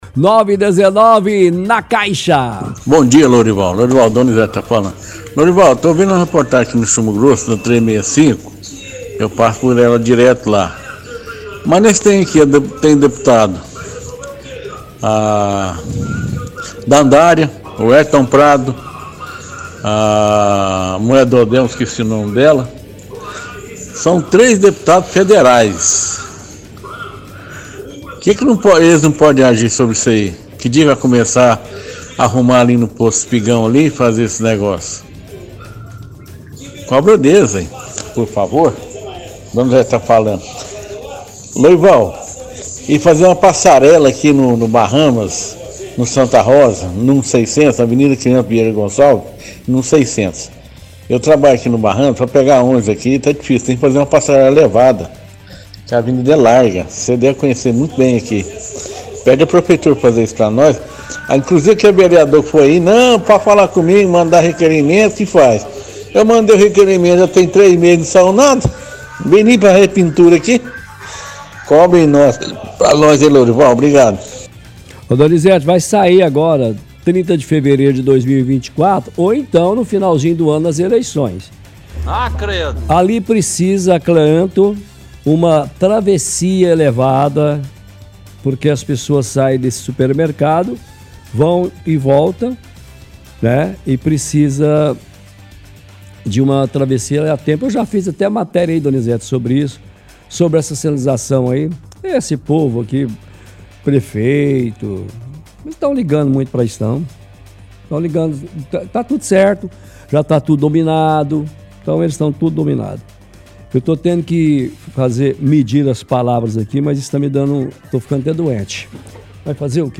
– Ouvinte reclama da situação da 365 e questiona porque os deputados, como Weliton Prado e a esposa do prefeito não fazem alguma coisa.